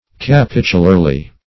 Search Result for " capitularly" : The Collaborative International Dictionary of English v.0.48: Capitularly \Ca*pit"u*lar*ly\, adv.